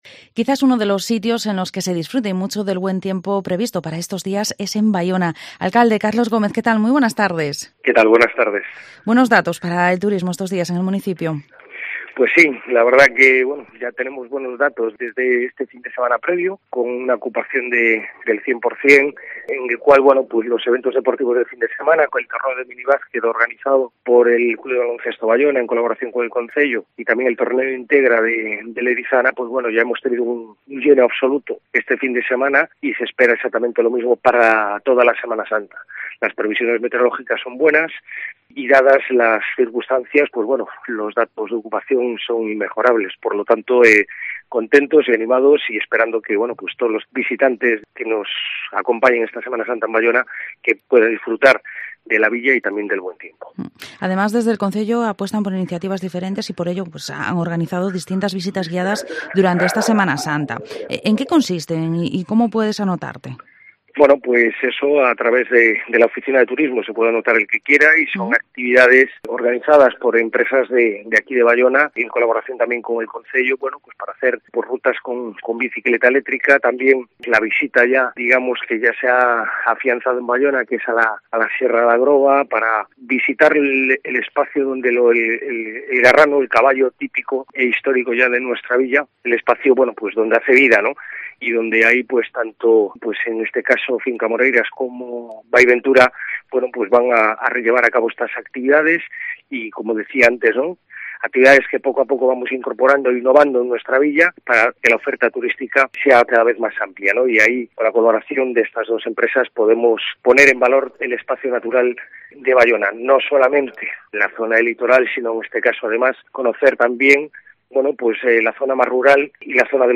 Entrevista al Alcalde de Baiona, Carlos Gómez